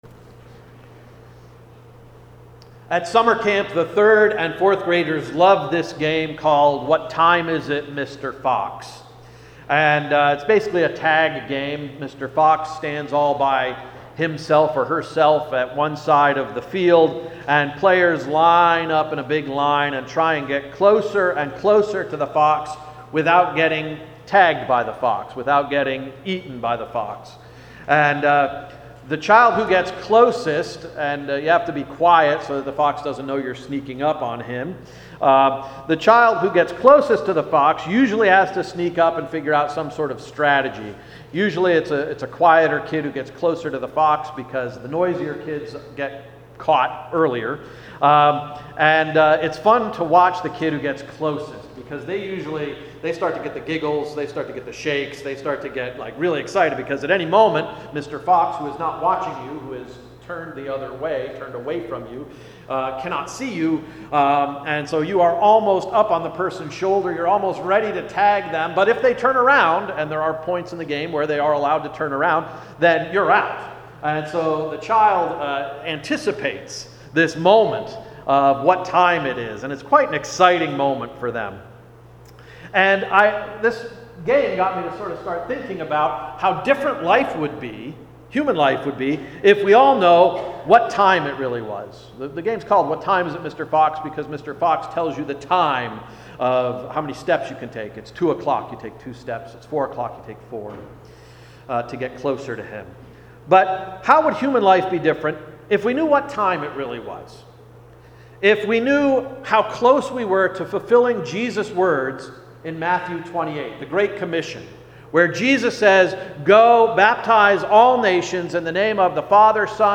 Sermon of September 16, 2018 — “A Moment Beyond Measure”